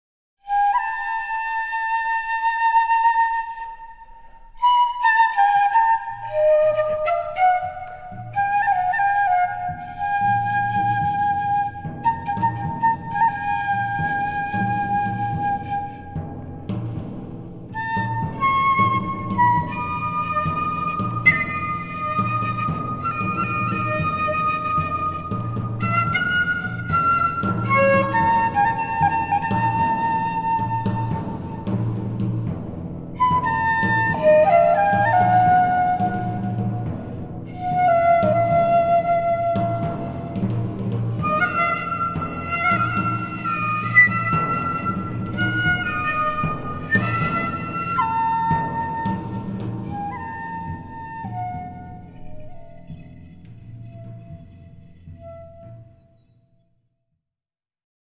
Sound Track
SYNTHESIZER
笛